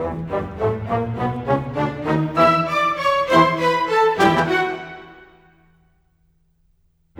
Rock-Pop 20.wav